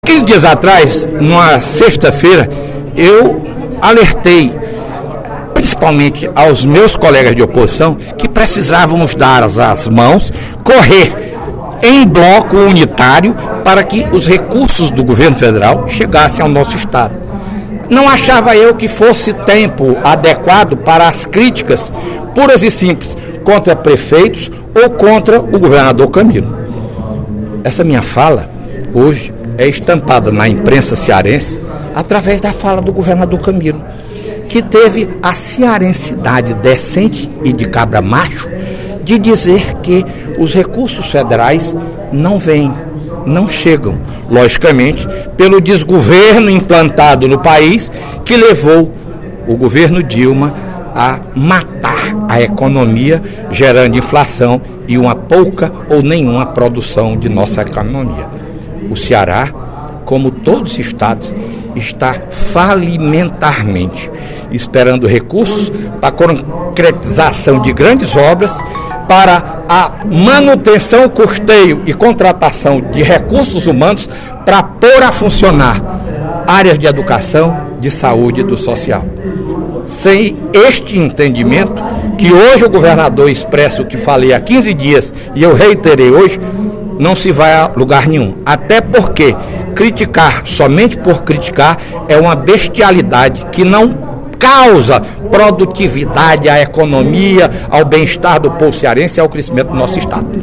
Durante o primeiro expediente da sessão plenária desta sexta-feira (10/04), o deputado Fernando Hugo (SD) comentou a entrevista do governador Camilo Santana ao jornal Diário do Nordeste, na qual o petista reconhece a situação crítica do Ceará.
Em aparte, o deputado Welington Landim (Pros) endossou a postura do governador, afirmando que os 100 dias da gestão de Camilo foram suficientes para mostrar o estilo de governar do petista.